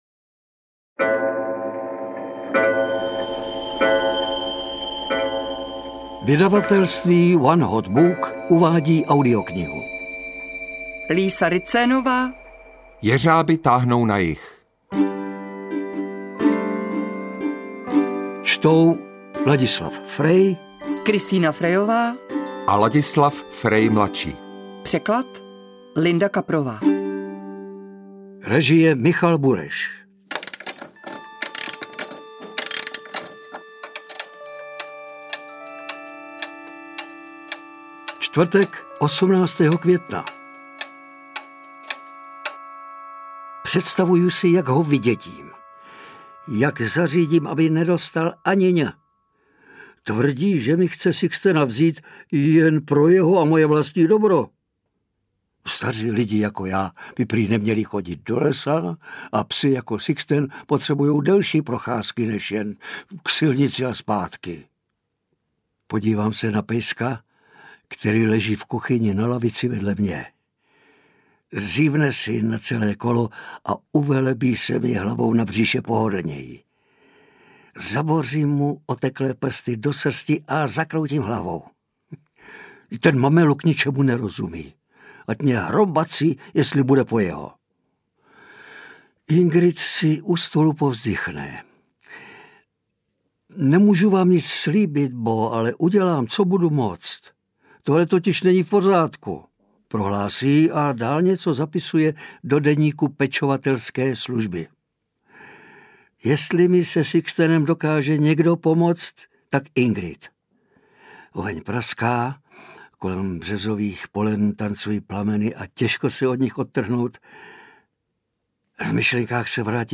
Interpreti:  Ladislav Frej, Kristýna Frejová
AudioKniha ke stažení, 33 x mp3, délka 8 hod. 31 min., velikost 456,0 MB, česky